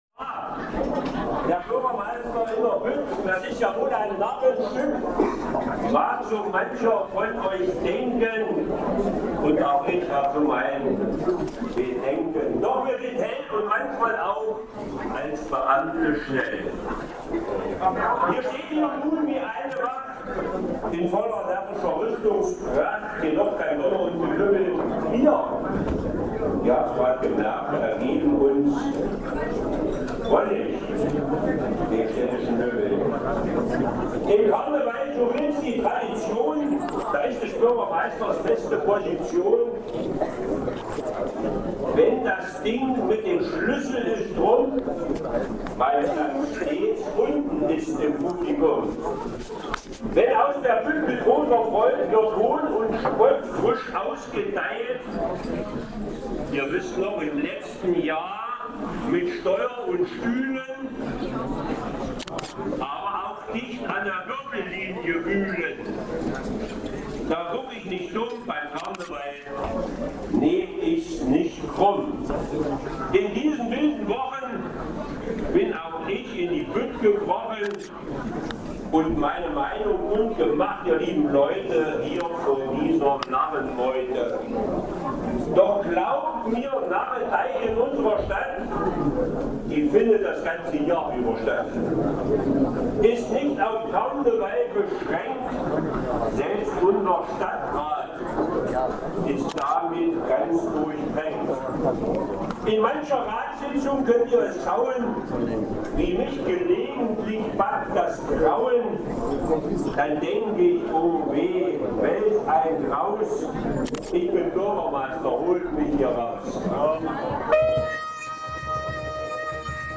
Für alle, die nicht dabei sein konnten haben die Eichsfelder Nachrichten die Bütt von Bürgermeister Gerd Reinhardt hier im Originalton mit entsprechender Karnevalatmosphäre (im Bild rechts bei der Schlüsselübergabe an die Worbiser).